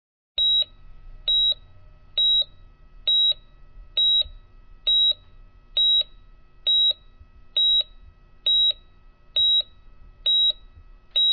alert.c5d286a4.wav